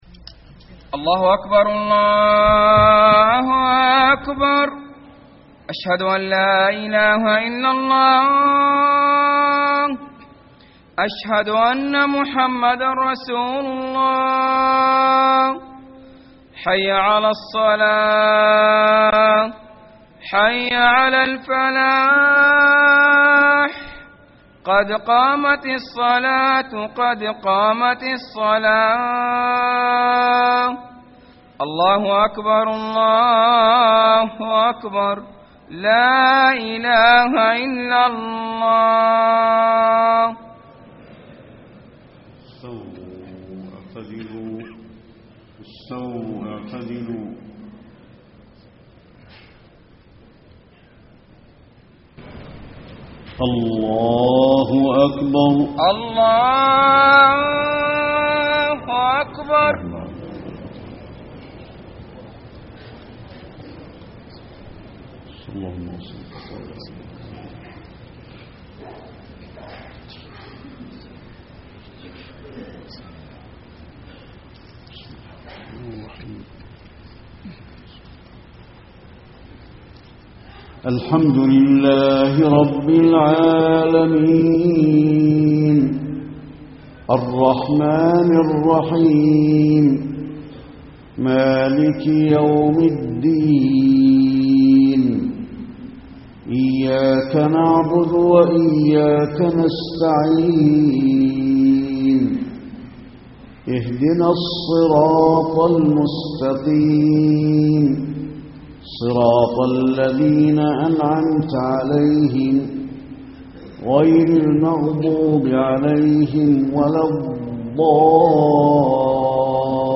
صلاة العشاء 7-8-1434 من سورة طه > 1434 🕌 > الفروض - تلاوات الحرمين